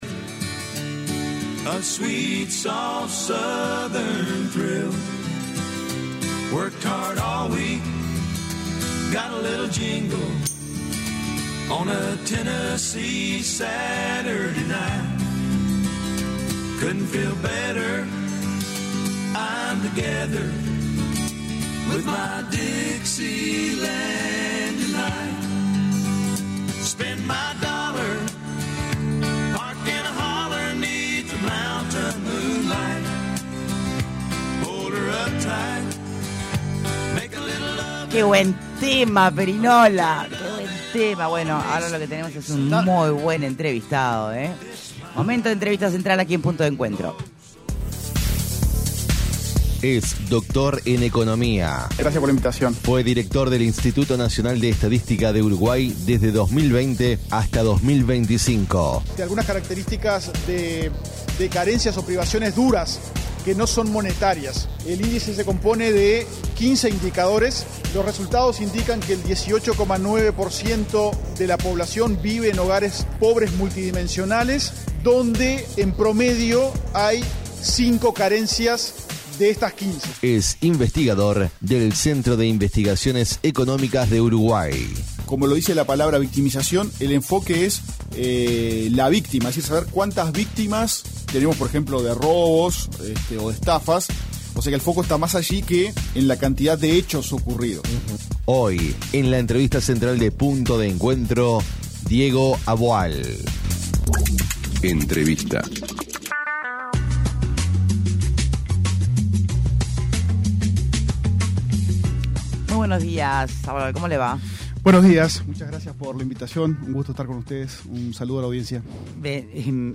El investigador del Cinve y exdirector del Instituto Nacional de Estadística, Diego Aboal señaló en entrevista con Punto de Encuentro que la decisión de congelar temporalmente el acuerdo entre la Agencia Nacional de Investigación e Innovación (ANII) con la Universidad de Jerusalén es una oportunidad perdida.